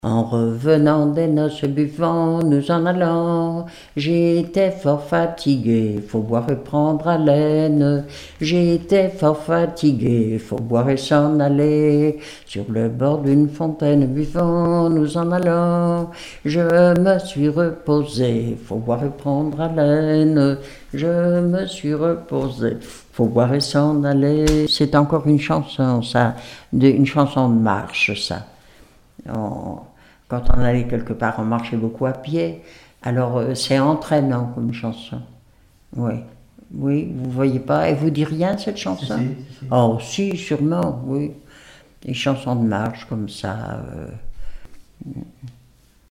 Fonction d'après l'analyste gestuel : à marcher ;
Genre laisse
Enquête Arexcpo en Vendée
Pièce musicale inédite